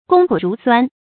攻苦茹酸 注音： ㄍㄨㄙ ㄎㄨˇ ㄖㄨˊ ㄙㄨㄢ 讀音讀法： 意思解釋： 謂勞苦艱辛。